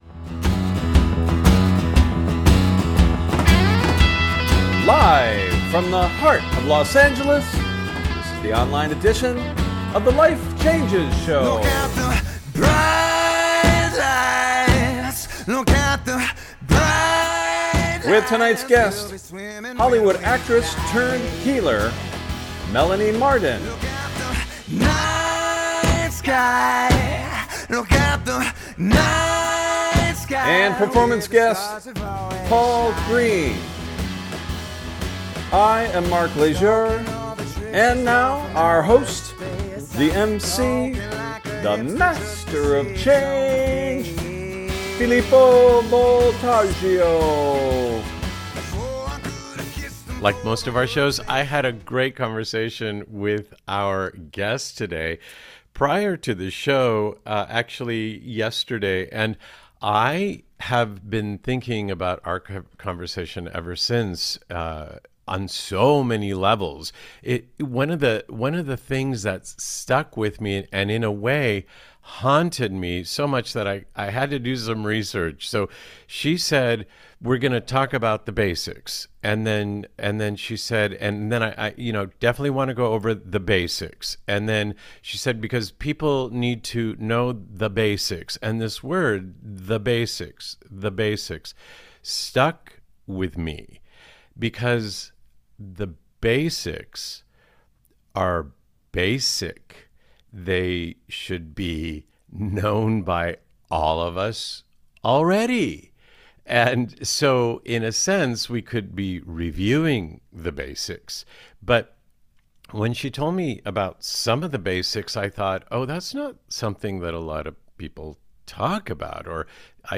Talk Show Episode
Featuring Interview Guest, Hollywood Actress Turned Healer, Filmmaker, and Author